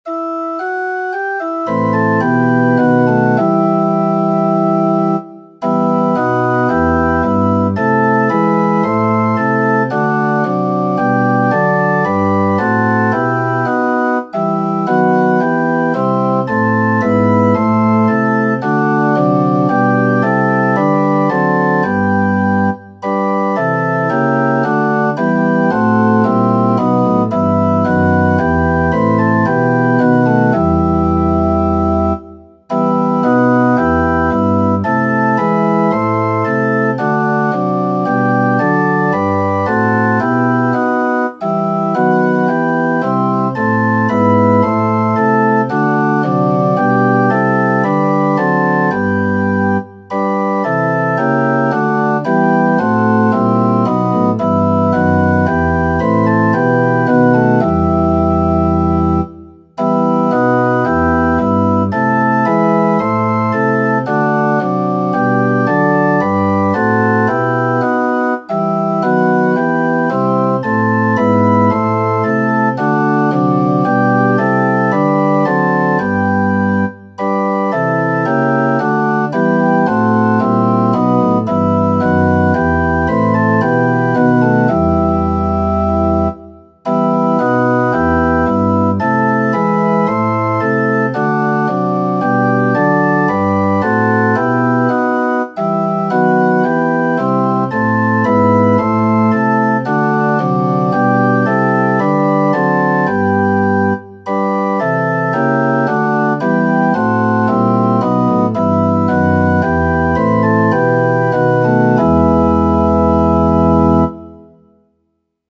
Culte préparé par une prédicatrice laïque - 24 novembre 2024 - Grenoble